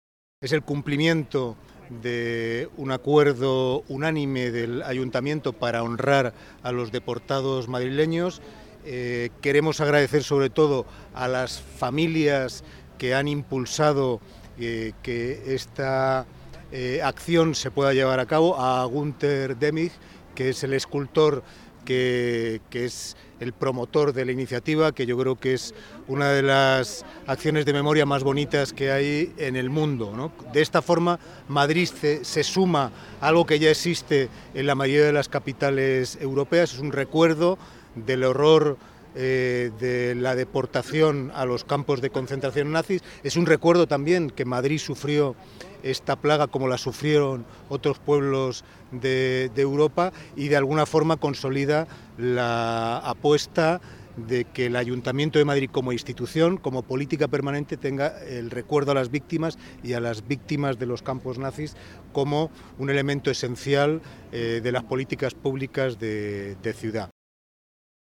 Nueva ventana:Mauricio Valiente en el acto homenaje a las victimas madrileñas deportadas a campos de concentración